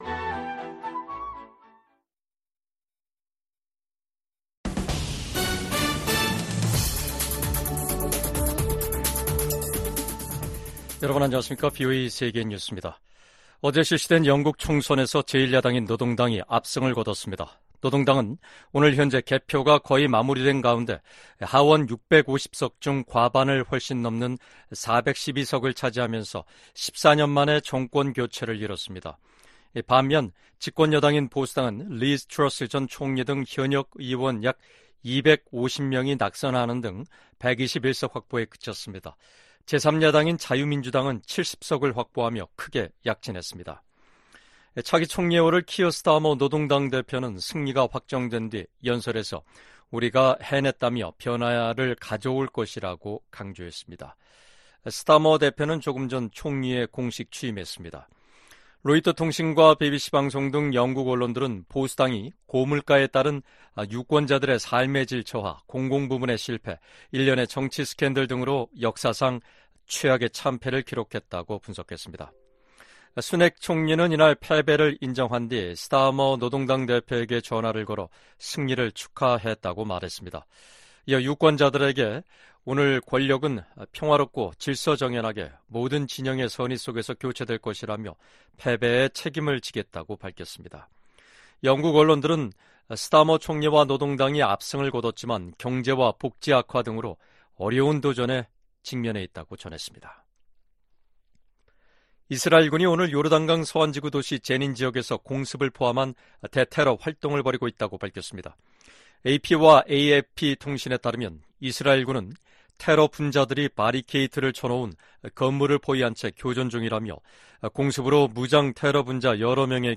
VOA 한국어 간판 뉴스 프로그램 '뉴스 투데이', 2024년 7월 5일 2부 방송입니다. 미 국무부는 북한 탄도미사일이 러시아의 우크라이나 공격에 사용됐다는 분석을 제시하며, 양국에 책임을 묻겠다고 경고했습니다. 북한을 비롯한 적성국들이 미국의 첨단 기술을 무단으로 사용하고 있는지 파악해 대응하도록 하는 법안이 미 하원에서 발의됐습니다.